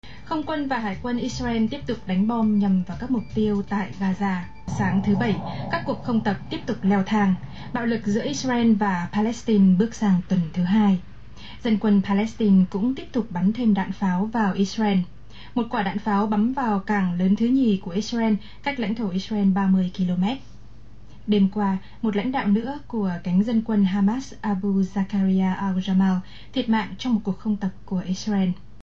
Here’s a recording of part of a news report in a mystery language.